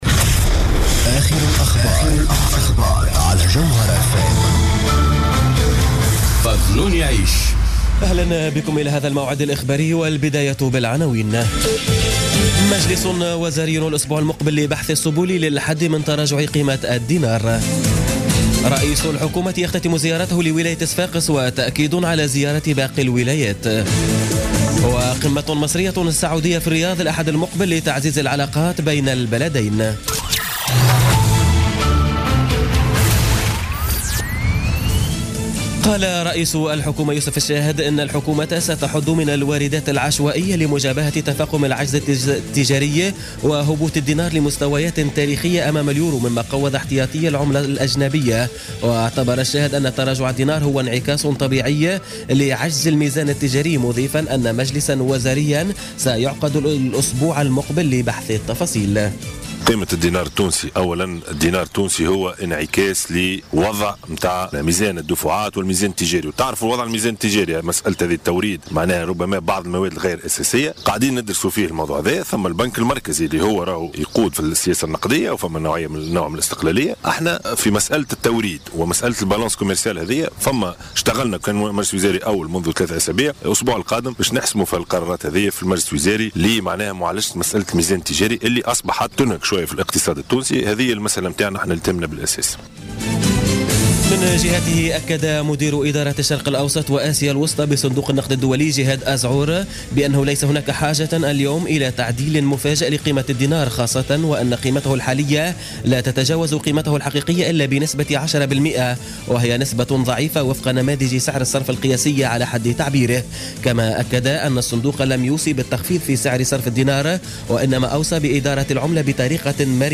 نشرة أخبار منتصف الليل ليوم السبت 22 أفريل 2017